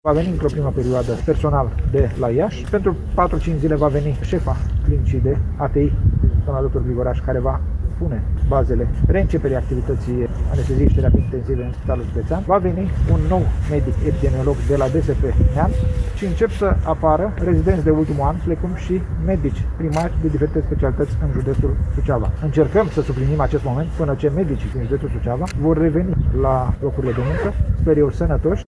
Ministrul Sănătăţii, Nelu Tătaru, a declarat, astăzi, la Suceava, că noua echipă managerială a Spitalului Judeţean de Urgenţă va reorganiza activitatea instiţuţiei sanitare şi evalua starea de sănătate a medicilor suceveni, care vor fi rechemaţi la muncă în momentul în care starea de sănătate le va permite.